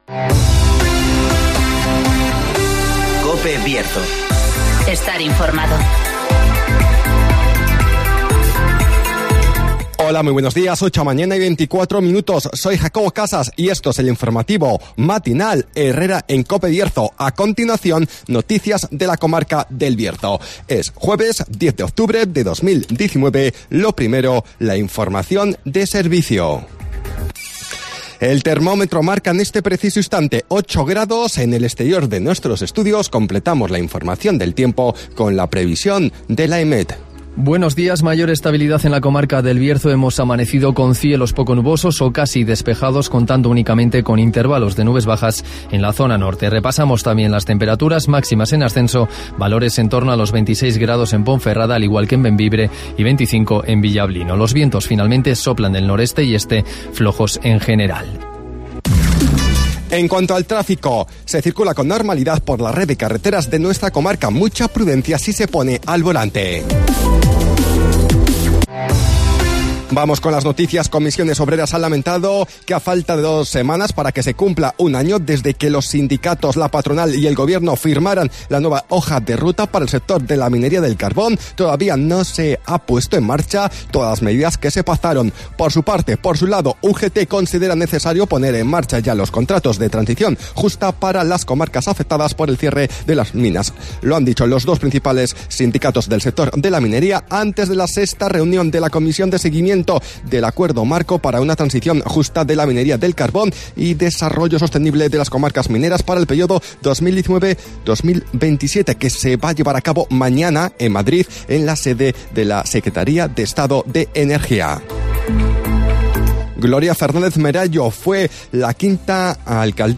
Conocemos las noticias de las últimas horas de nuestra comarca, con las voces de los protagonistas